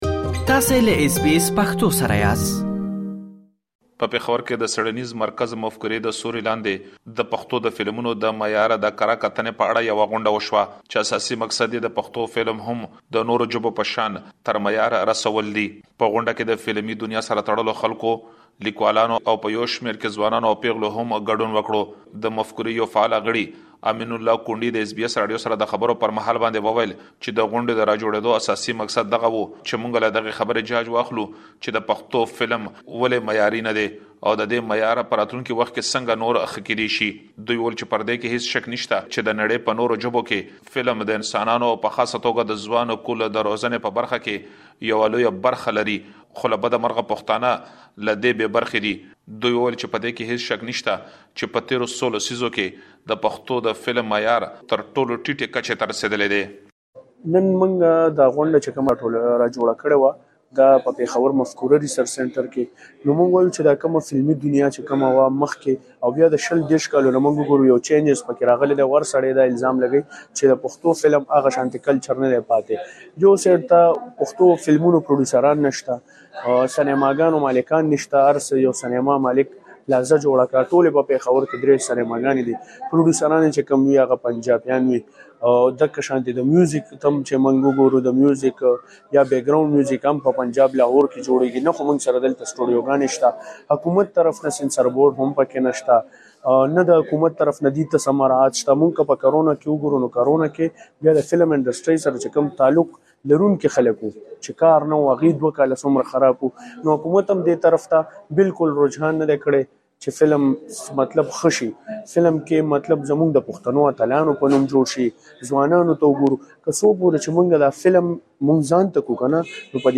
په پېښور ښار کې د پښتو فلمونو د کیفیت د ښه والي لپاره یوه غونډه جوړه شوې. په دغه غونډه کې لیکوالانو او هنرمندانو برخه اخیستې ترڅو د پښتو فلمونو په اړه بحث وکړي. مهرباني وکړئ جزئیات په رپوټ کې واورئ.